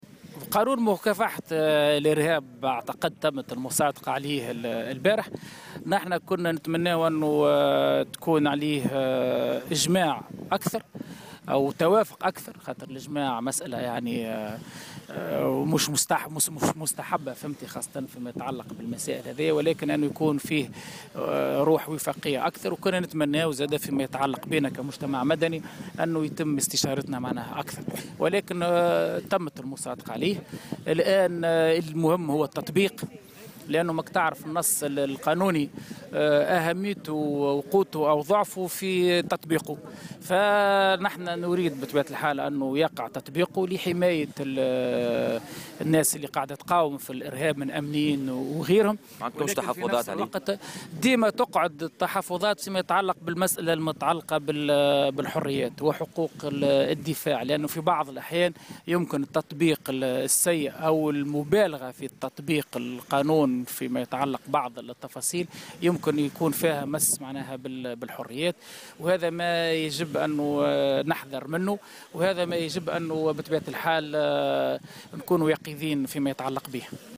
عبّر العميد السابق للمحامين شوقي الطبيب في تصريح ل"جوهرة أف أم" اليوم السبت عن وجود مخاوف من المس من الحريات بخصوص قانون الإرهاب.